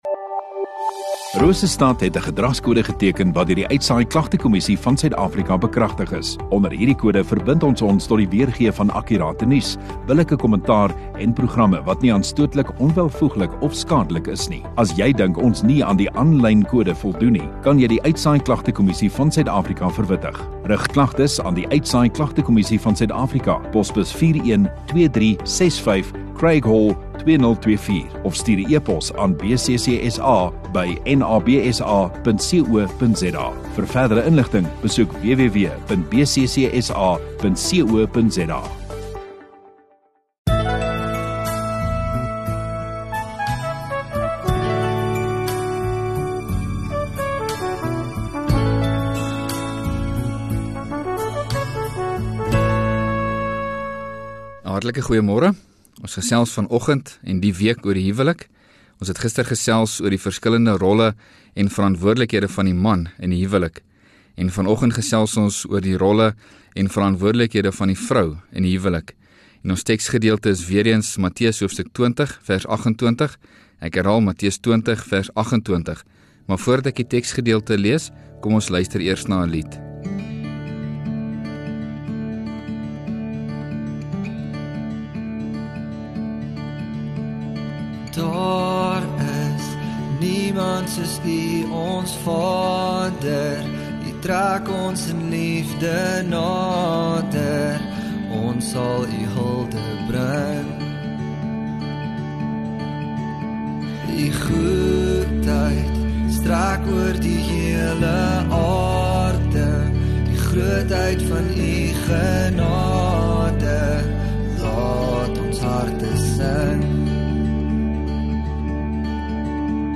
17 Nov Sondagoggend Erediens